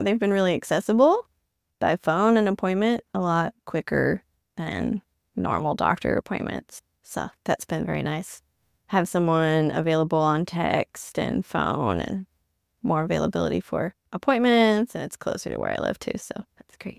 Testimonial 4 - WIC participant